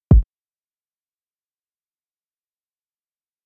kick 11.wav